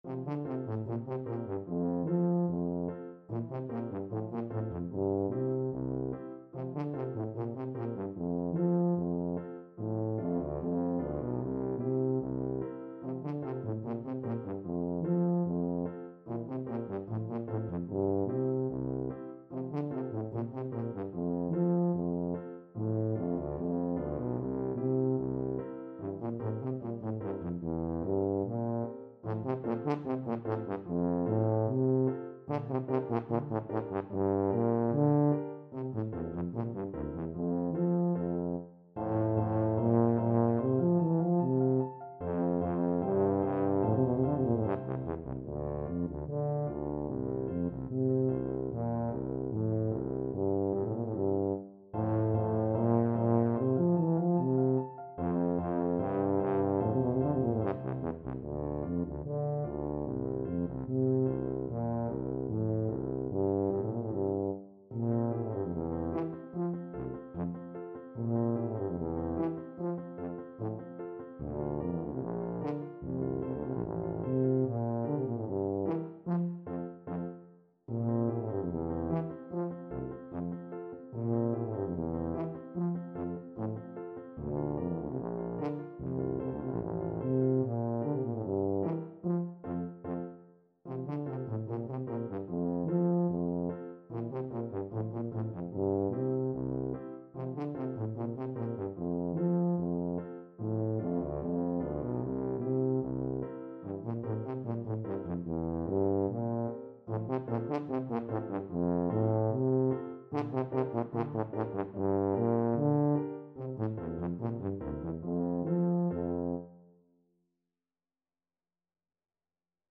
2/2 (View more 2/2 Music)
Allegretto = 74
A2-F4
Classical (View more Classical Tuba Music)